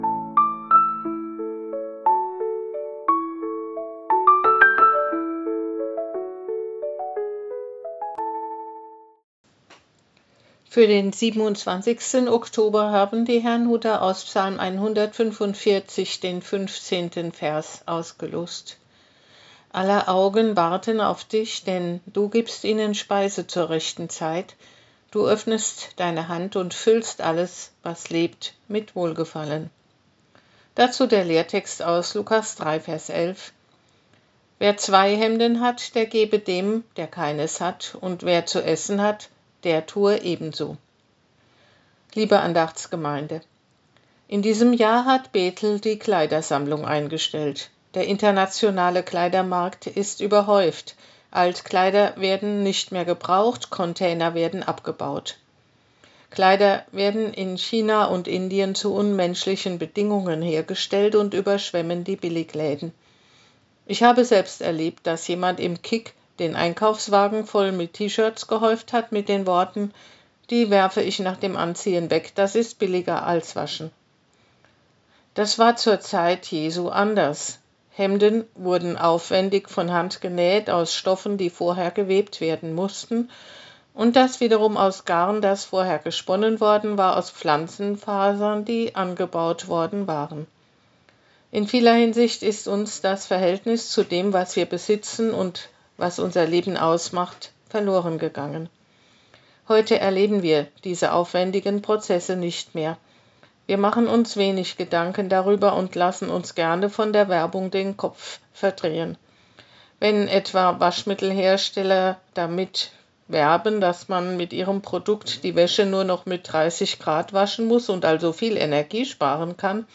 Losungsandacht für Montag, 27.10.2025